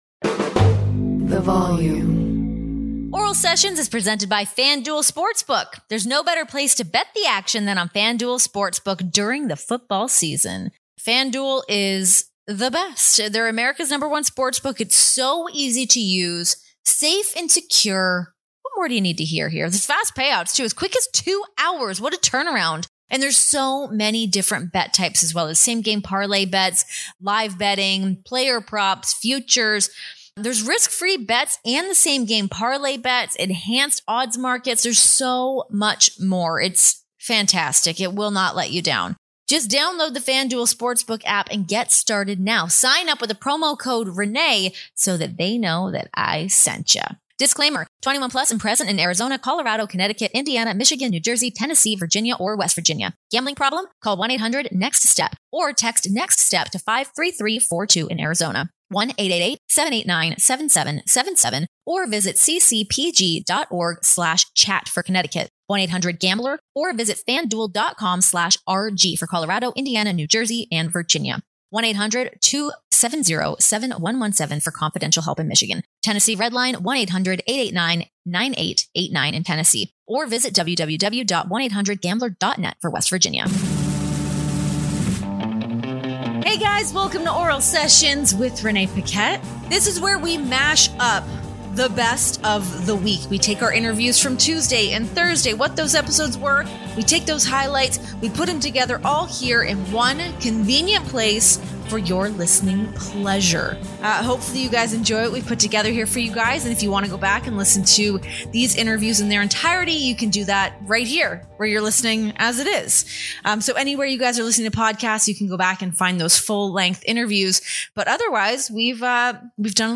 Relive the best of The Sessions this week, featuring highlights from our interviews with Mercedes Martinez and Gail Kim.